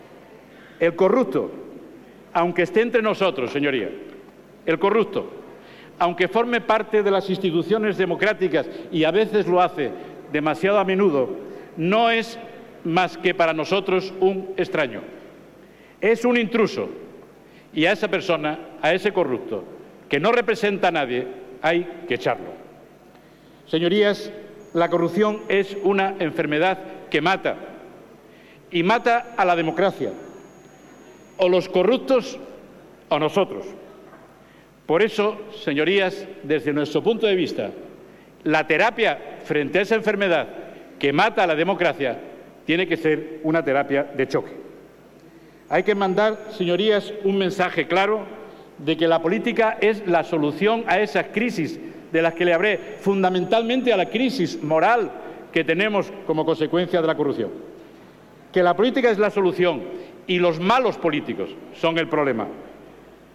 Javier Barrero en el pleno del 29/10/2013 pide que la Audiencia Nacional asume la investigación de los casos de corrupción política